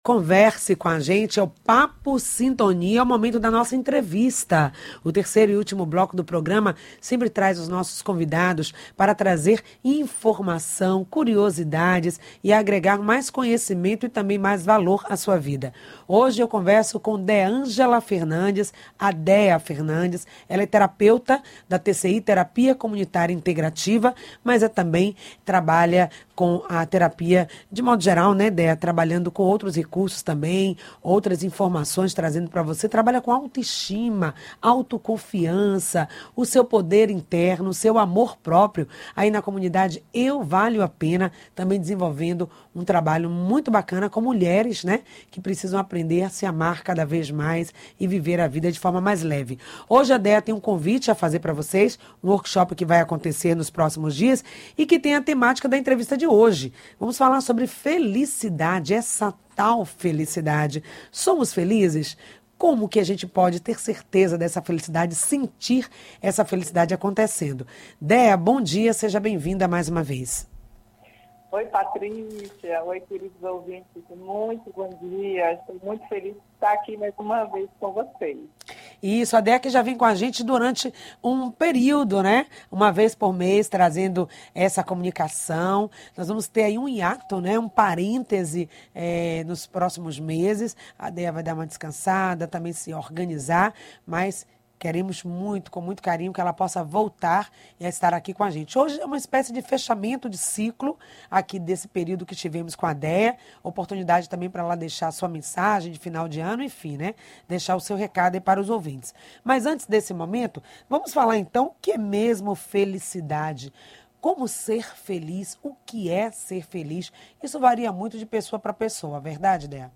O programa Em Sintonia acontece de Segunda à sexta das 9 às 10h, pela Rádio Excelsior AM 840.